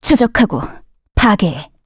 Valance-Vox_Skill1_kr.wav